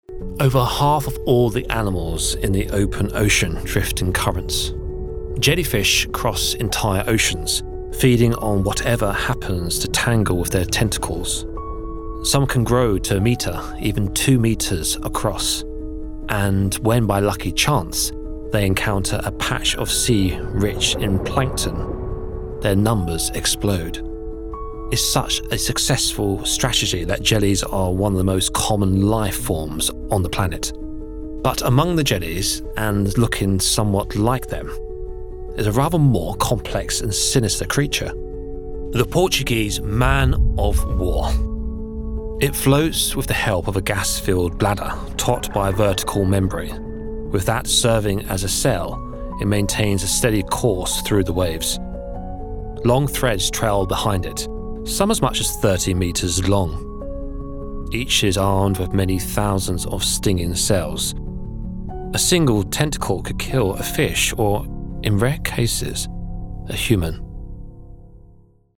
Documentaires
Mon accent est celui de l'estuaire, mais la plupart me connaissent comme un gars de l'Essex.
Baryton